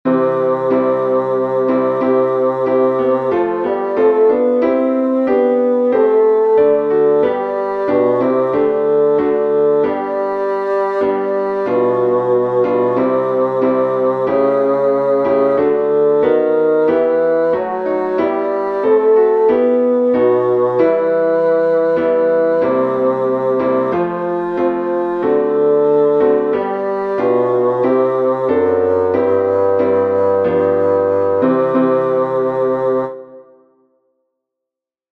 Bass
eternal_father-pd-bass.mp3